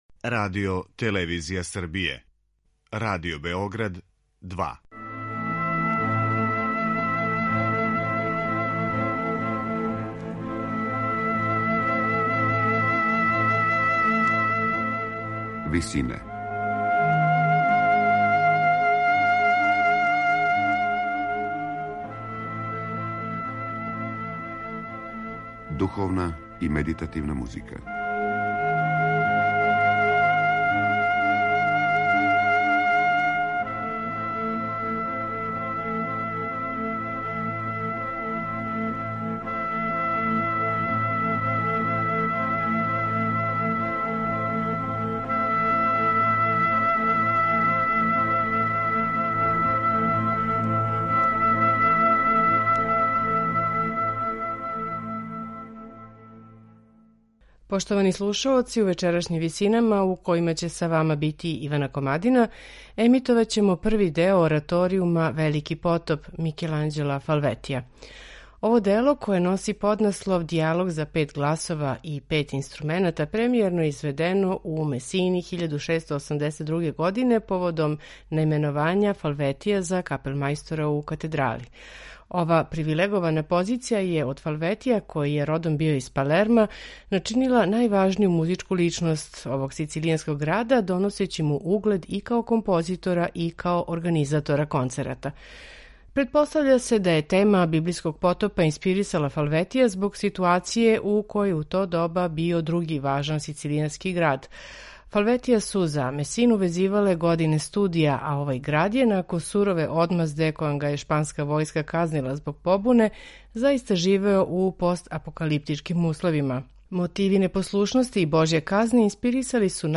Ораторијум „Велики потоп” Микеланђела Фалветија премијерно је изведен у Месини 1682. године, поводом наименовања Фалветија за каплемајстора у катедрали.